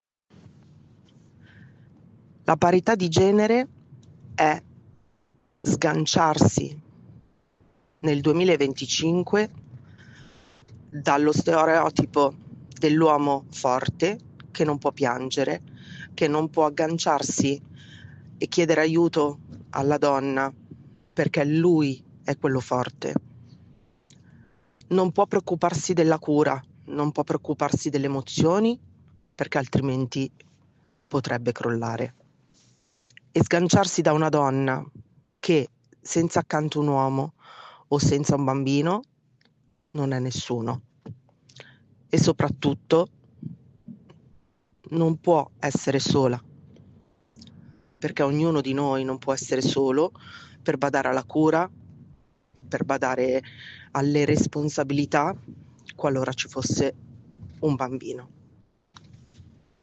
CON UN MESSAGGIO VOCALE